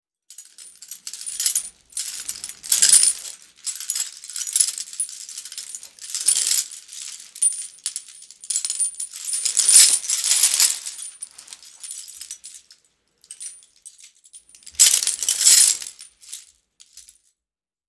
Звуки кандалов
На этой странице собраны звуки кандалов: звон цепей, скрежет металла, тяжелые шаги в оковах.